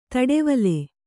♪ taḍevale